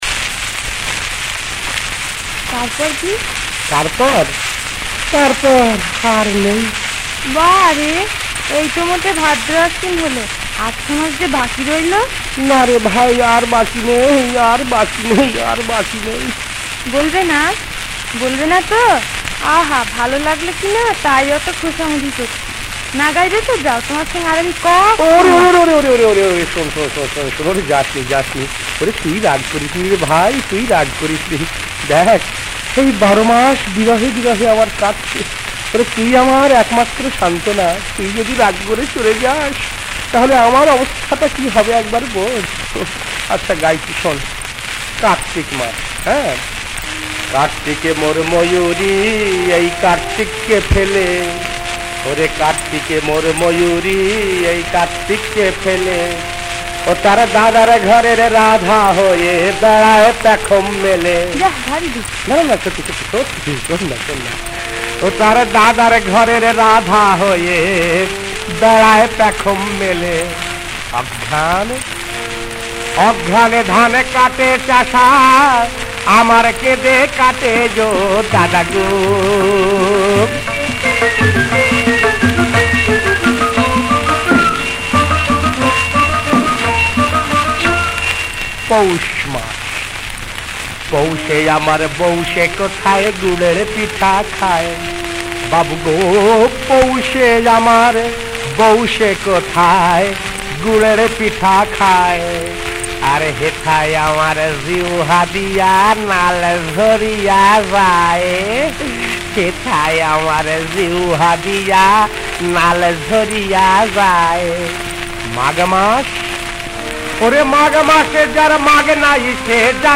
• বিষয়াঙ্গ: হাসির গান
• সুরাঙ্গ: লোকসুর (বারোমাসী)
• তাল: তালফেরতা (দাদরা/ কাহারবা)
• গ্রহস্বর: সা